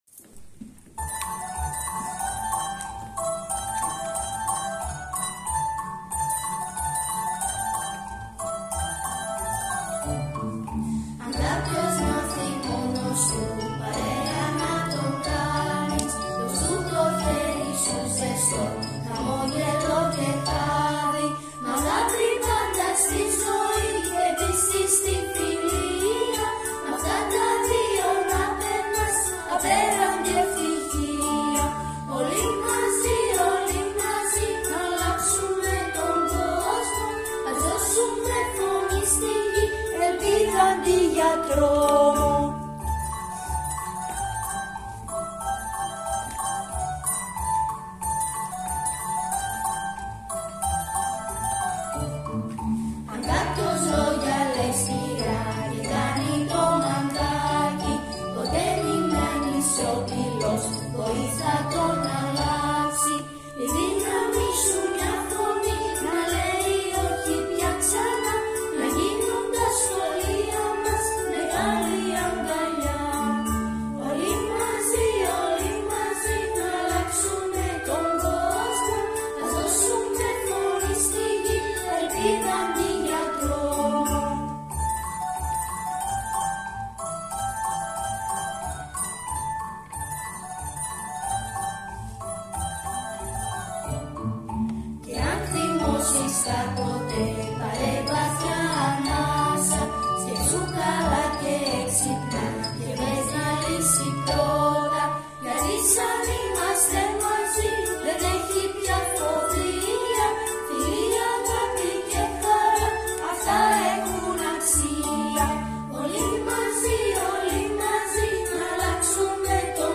Στα πλαίσια του προγράμματος ΠΑΒΙΣ με θέμα τον εκφοβισμό, οι μαθητές της Δ΄ τάξης έχουν γράψει και έχουν μελωποιήσει το τραγούδι: